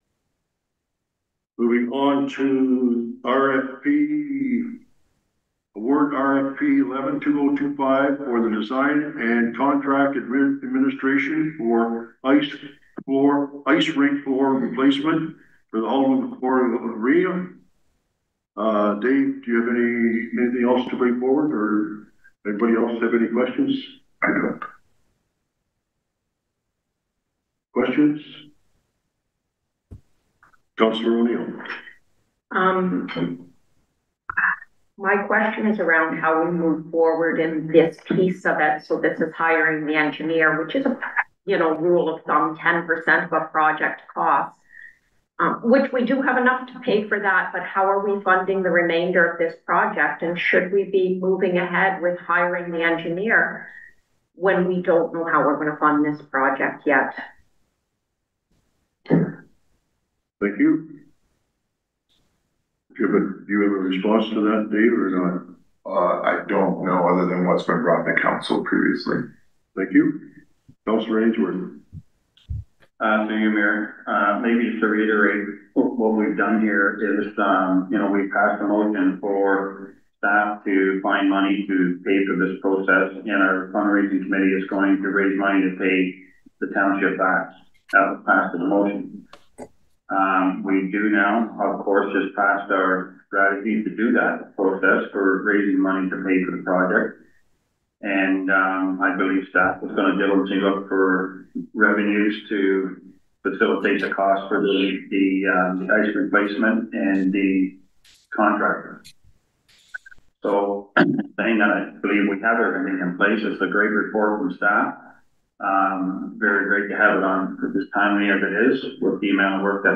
Memorial Arena, which is more than 75 years old, will get a new ice pad after Alnwick-Haldimand Township council approved a $78,000 proposal for Barry Brian Associates at its regular council meeting on Dec. 23.
DEC-23-ICE-PAD-FLOOR-RFP-DEBATE.FINAL_.mp3